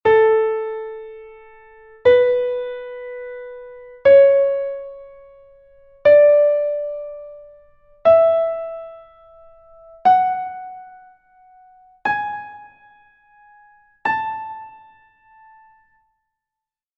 ascending.mp3